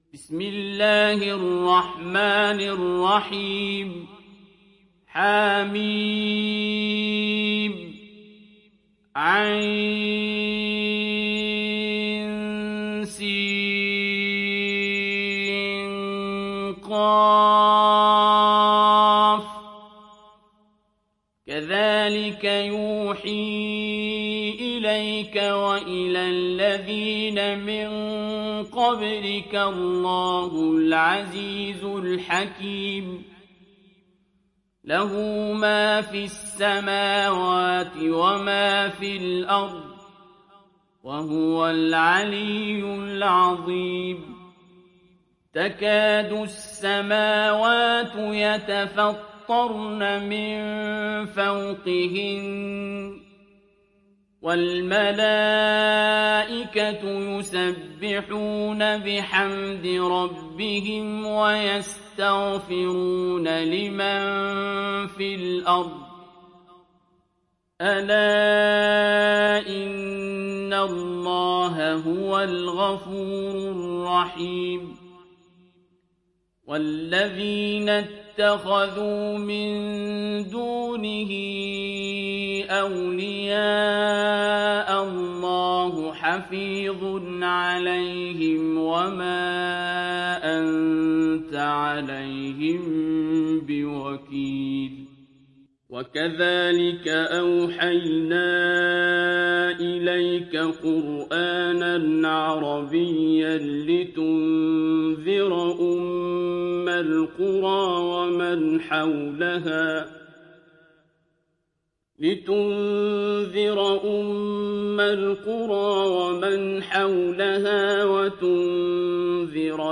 Surat Ash Shura mp3 Download Abdul Basit Abd Alsamad (Riwayat Hafs)
Surat Ash Shura Download mp3 Abdul Basit Abd Alsamad Riwayat Hafs dari Asim, Download Quran dan mendengarkan mp3 tautan langsung penuh